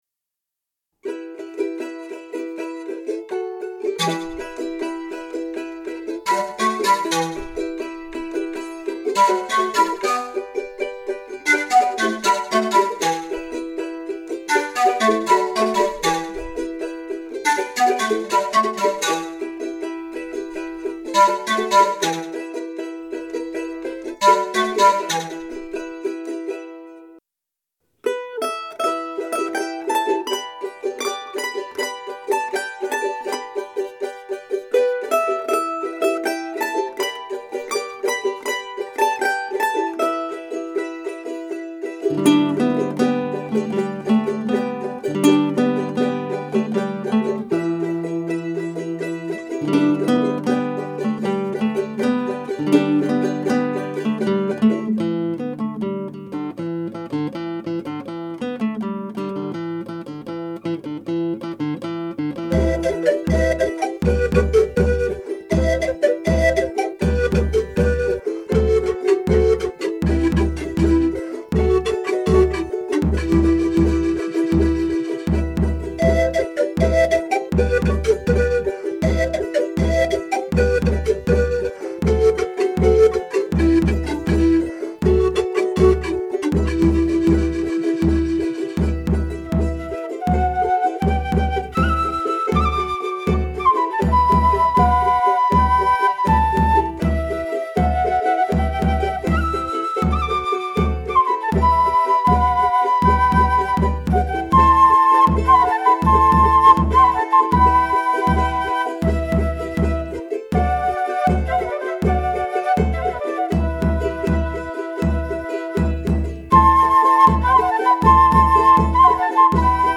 別々に録音した楽器やパソコンで作ったパーカッションをミックスして完成します。
スタジオは鍼灸院の待合室です。